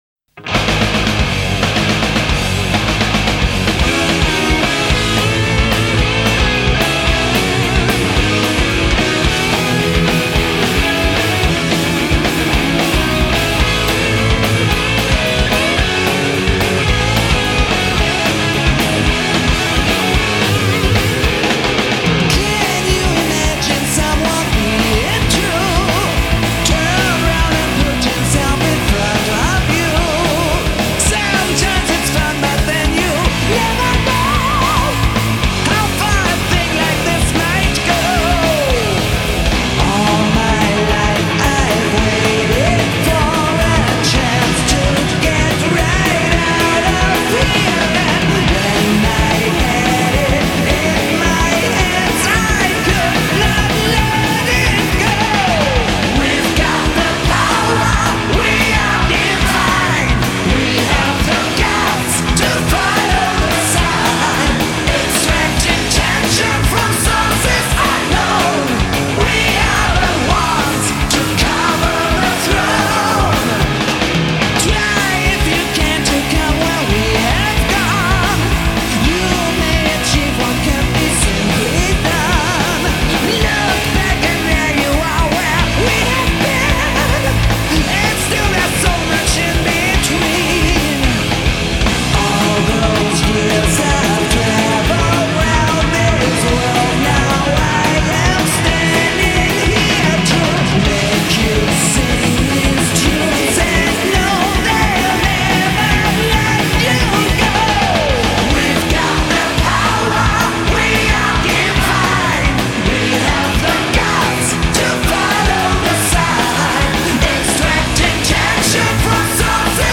بهترین آهنگ های سبک متال
بهترین و پیشروترین گروه پاور متال